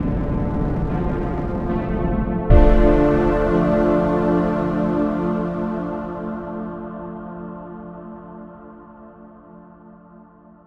Synth Pad 3